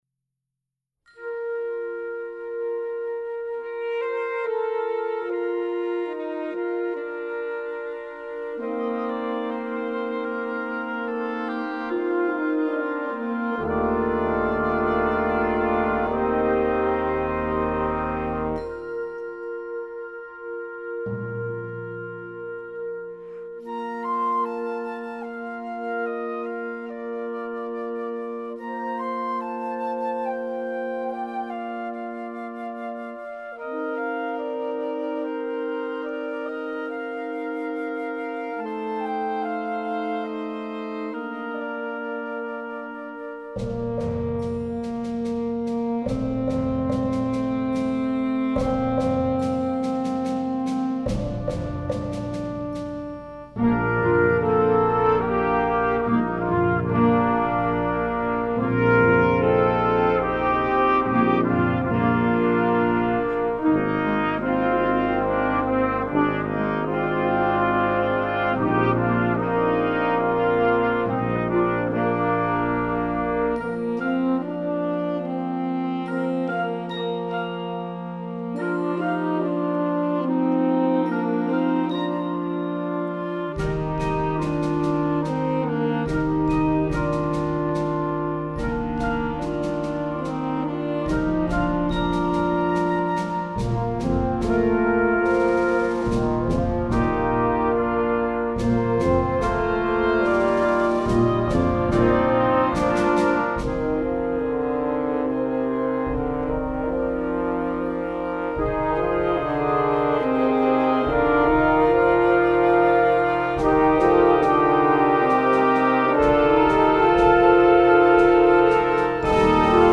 Heartfelt and kind
Concert Band